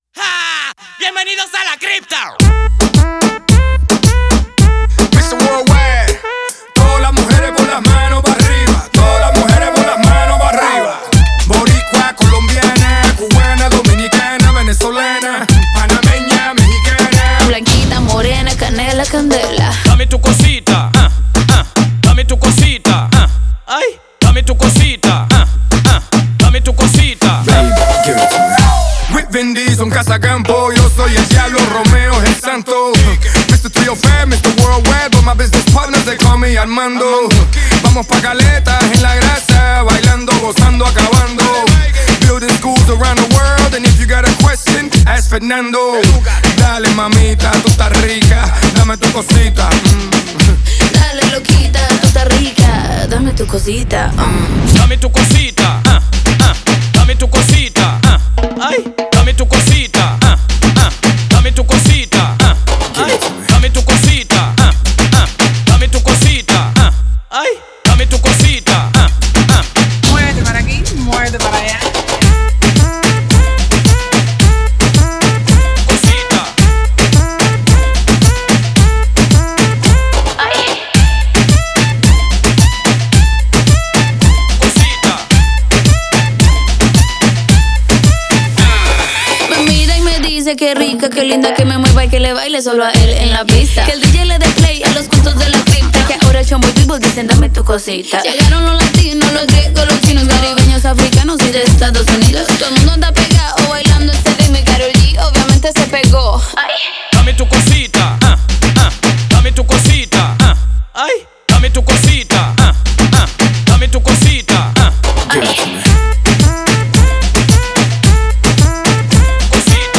Latin song